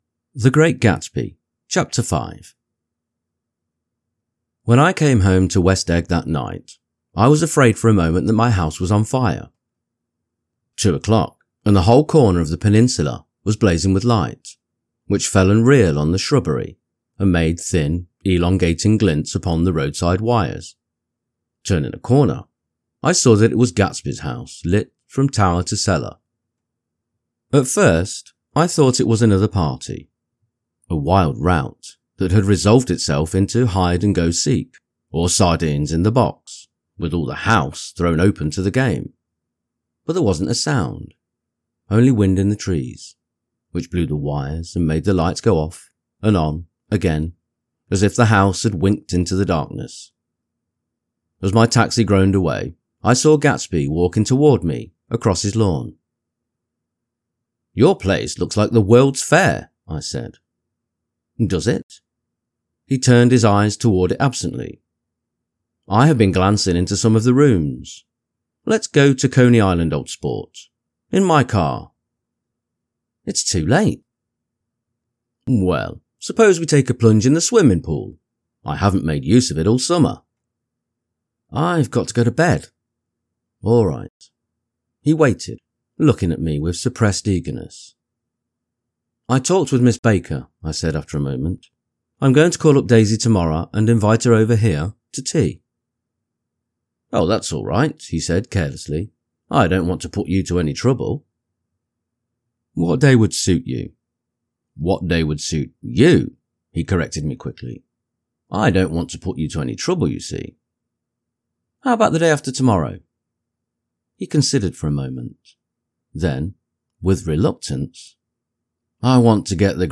The Great Gatsby Audio-book – Chapter 5 | Soft Spoken English Male Full Reading (F.Scott Fitzgerald) - Dynamic Daydreaming